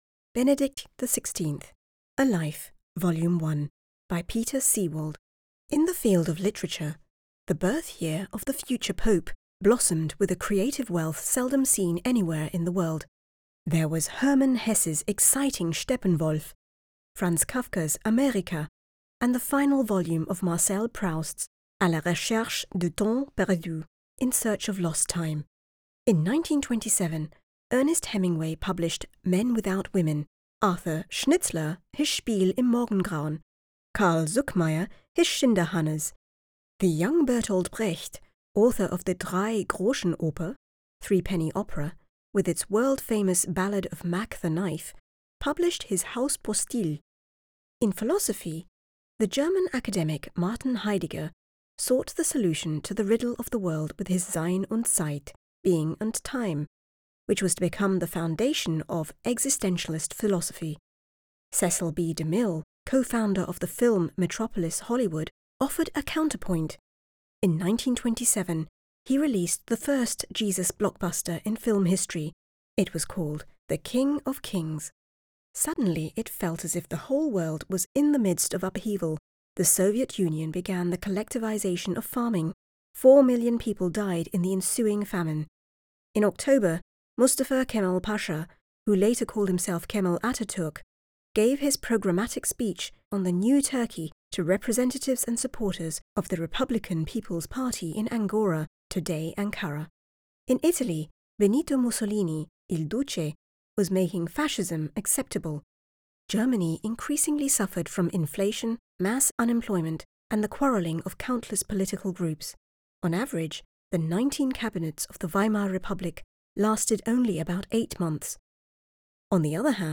Narration: Longform Excerpt from 'Benedict XVI: A Life'
I have a warm, clear voice and am capable of expressing a broad range of tones and energies so that I can deliver performances spanning the soothing and mellow to the dynamic and authoritative.
I record in an accoustically treated home studio environment to minimize echoes, noise and reverberation.
To complement the treated studio setting, I use premium microphones: Neumann TLM  103 and Sennheiser MKH 416.